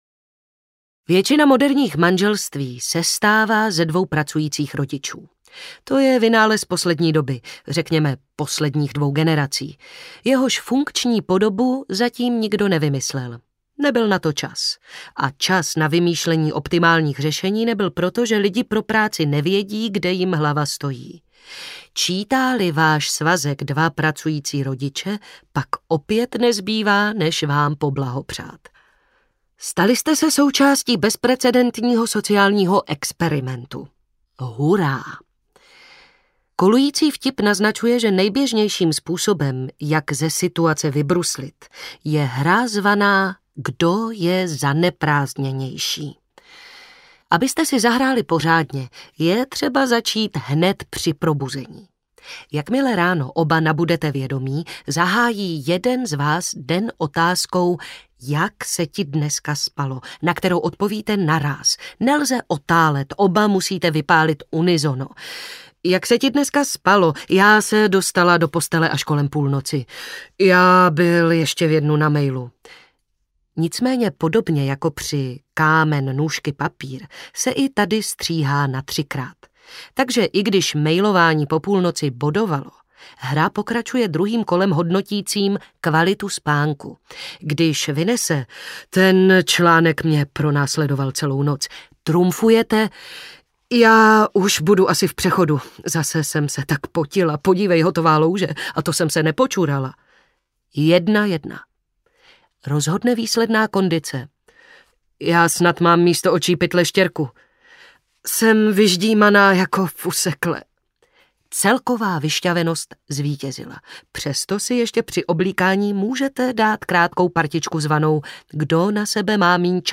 Víc než žena audiokniha
Ukázka z knihy
| Vyrobilo studio Soundguru.